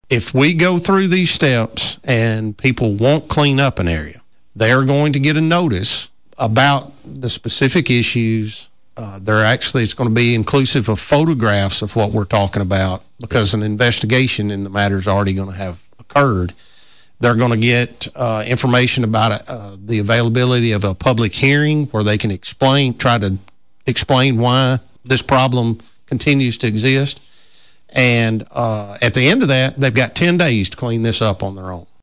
Martin Police Chief Philip Fuqua: